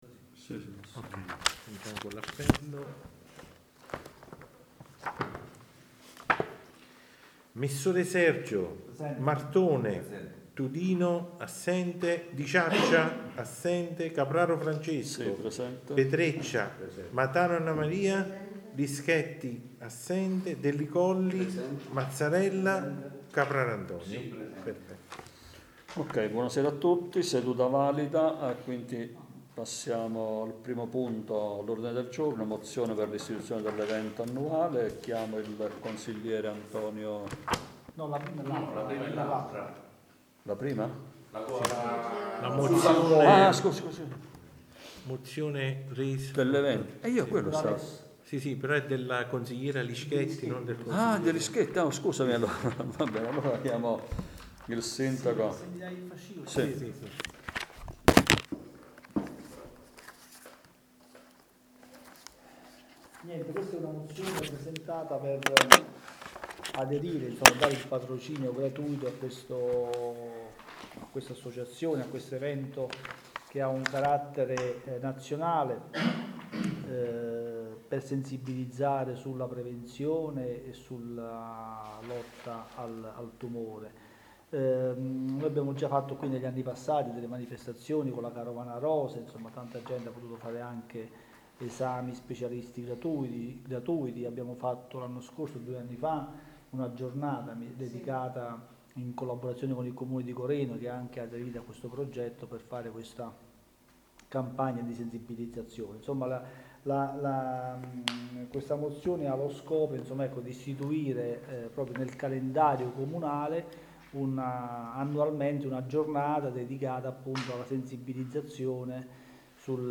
Registrazione seduta consiliare del 23.4.2025